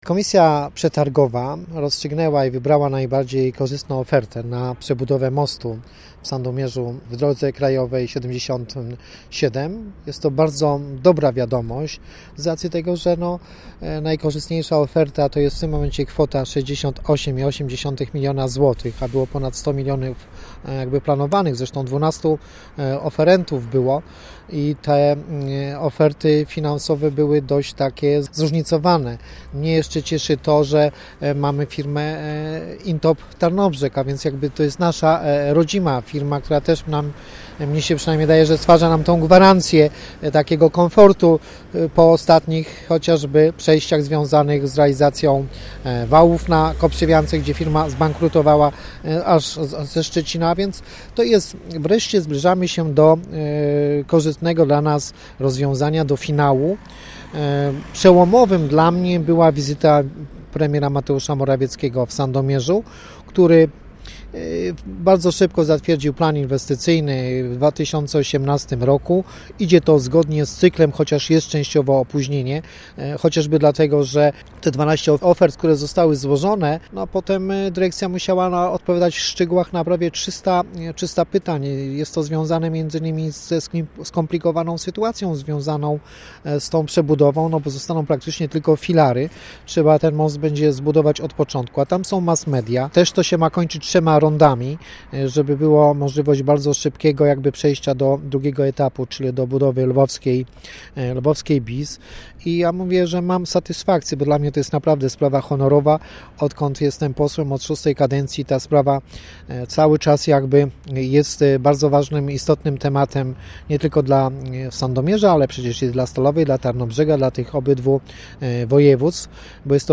– To inwestycja ważna nie tylko dla mieszkańców Sandomierza – mówi poseł Marek Kwitek, który od lat czynił starania o jej realizację: